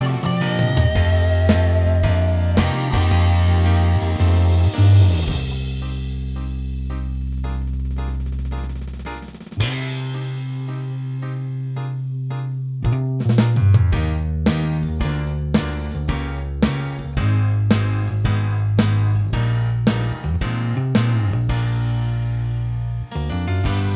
Minus Lead Guitar Rock 3:07 Buy £1.50